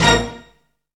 STRING HIT 3.wav